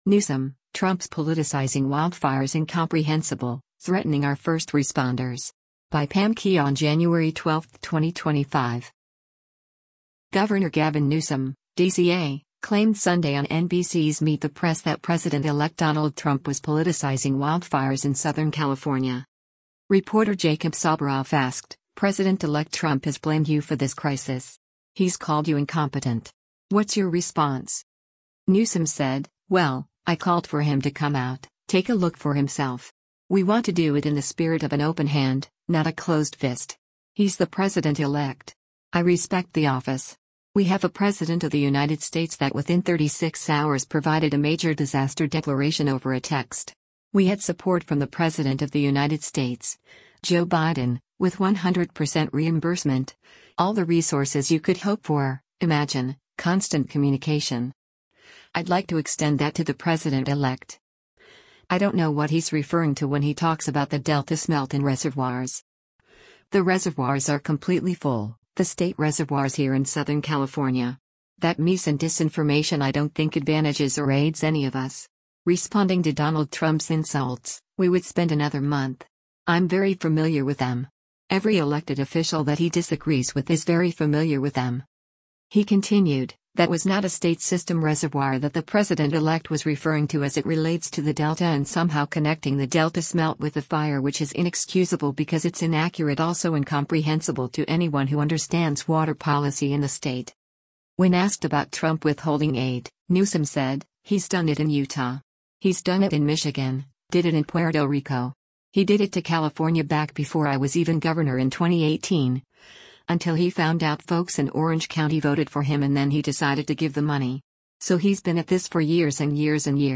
Governor Gavin Newsom (D-CA) claimed Sunday on NBC’s “Meet the Press” that President-elect Donald Trump was politicizing wildfires in Southern California.